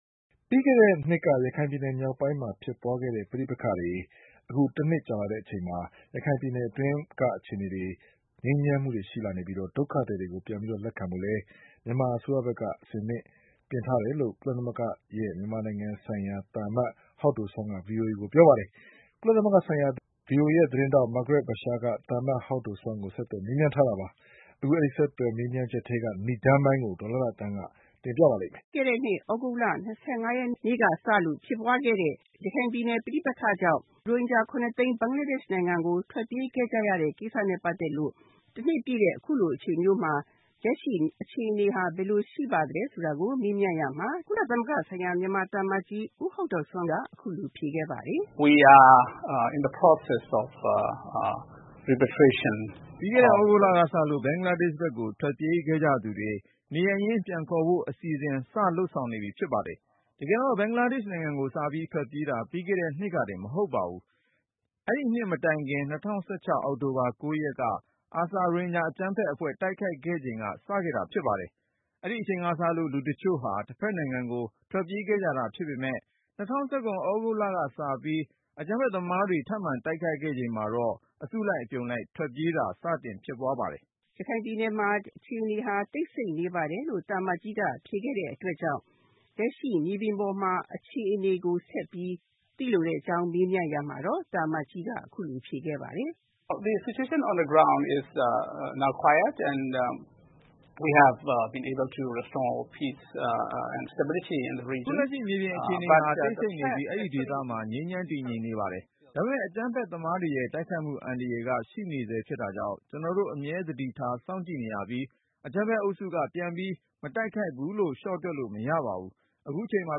ရိုဟင်ဂျာပဋိပက္ခ တနှစ်ပြည့်ချိန် ကုလ မြန်မာသံအမတ်ကြီးနဲ့ VOA မေးမြန်းခန်း